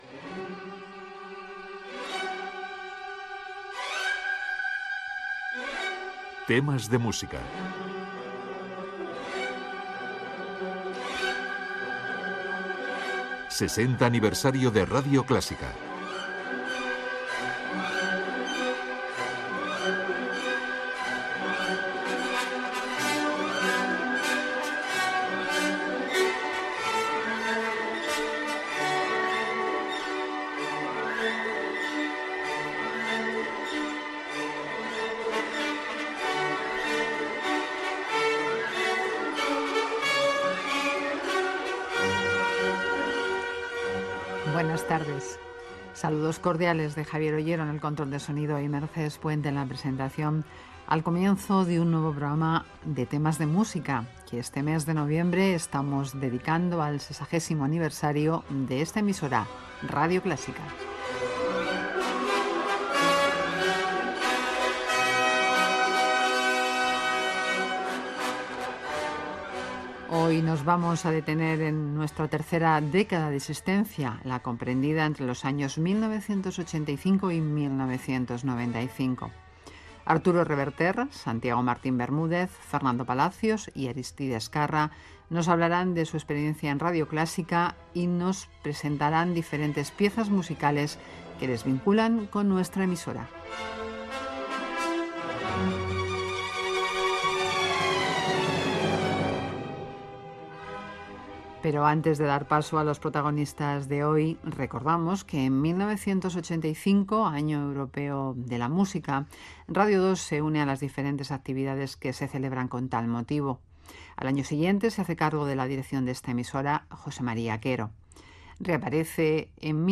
Careta del programa, espai dedicat als 60 anys de Radio Clásica, la dècada de 1985 a 1995
Gènere radiofònic Musical